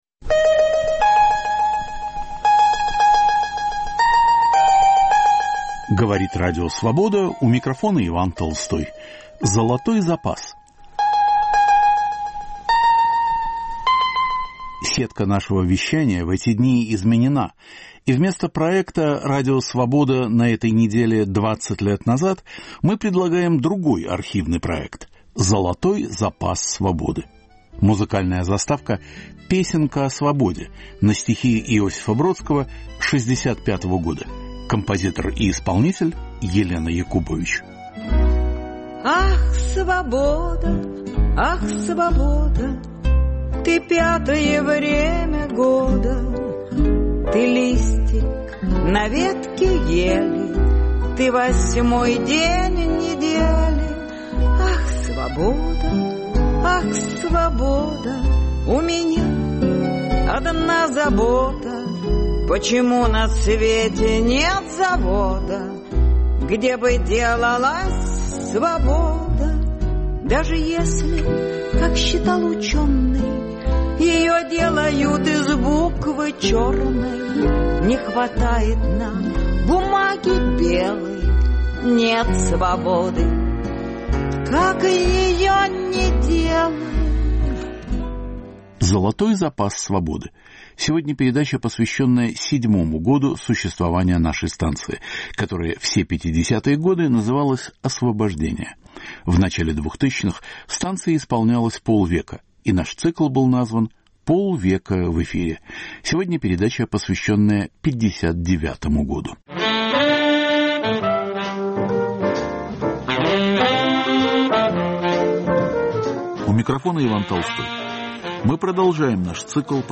Цикл передач к 50-летию Радио Свобода. Год 1959: что сохранилось в архиве?